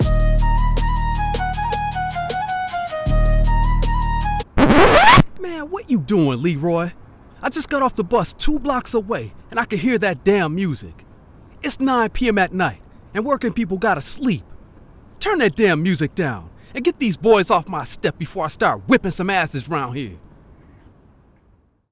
Authoritative African American Character Voice - Animation
North American, African American, Southern, Eastcoast
I record projects using the Focusrite Scarlet Solo, CM25 MkIII condenser mic and REAPER DAW.